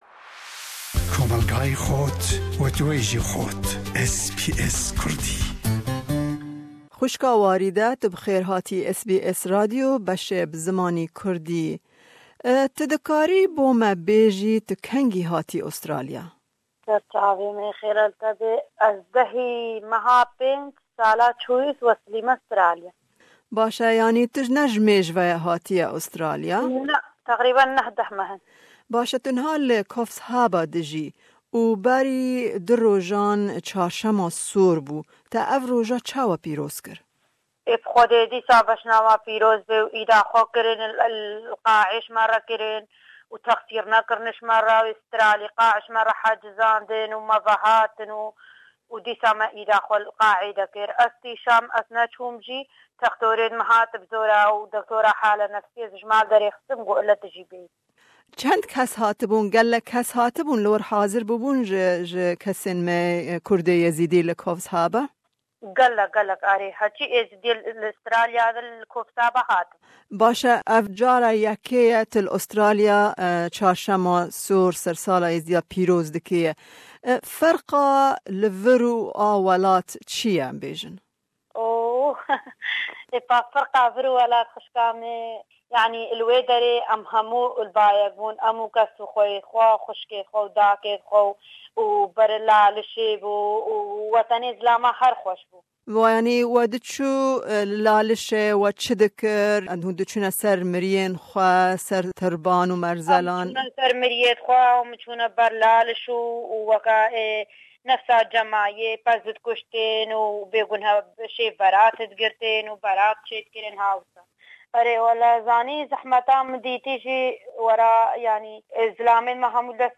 Em derbarê pîrozbahiyê bi hin endamên ji civaka Êzîdî li Toowoomba û Coffs Harbour re li ser helwêsta wan ku cara yekemîne cejnê li Australiya pîroz dikine axifîn.